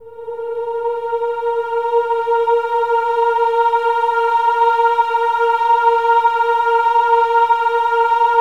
OH-AH  A#4-L.wav